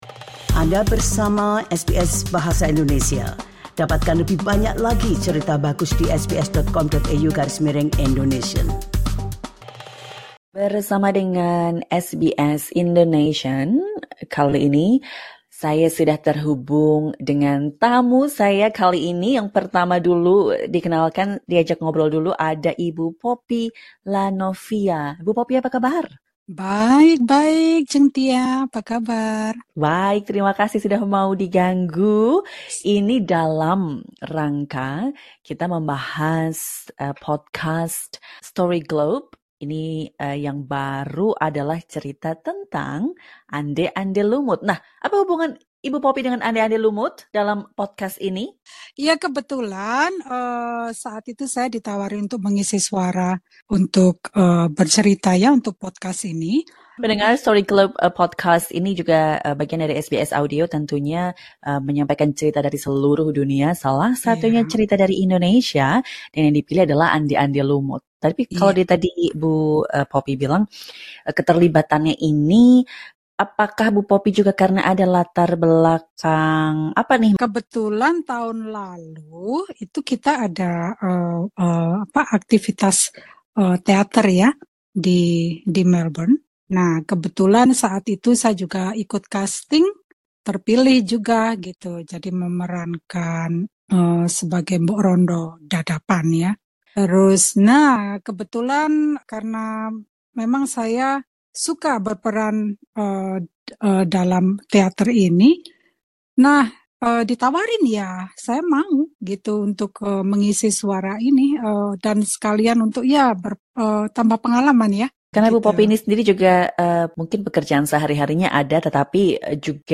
Simak perbincangan SBS Indonesian bersama pengisi suara podcast